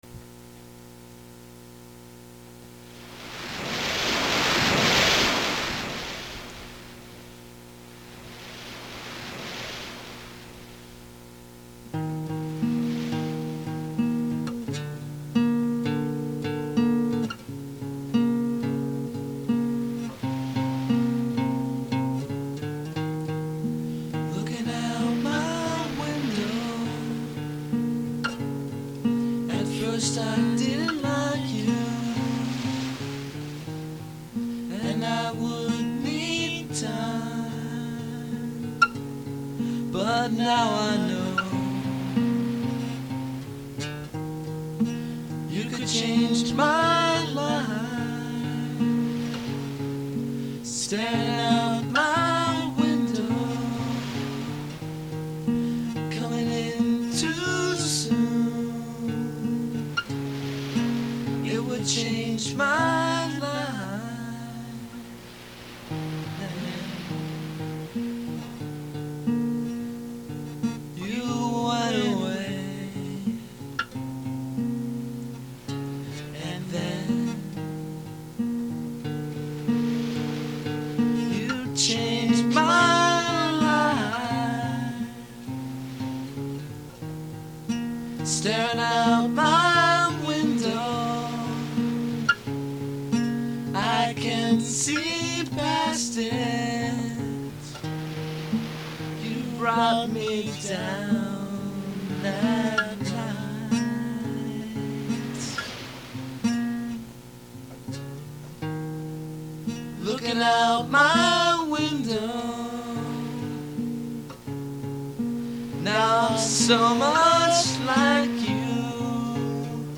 vocals, guitars
auxillary percussion We consider our music to be a style of Popslashrock.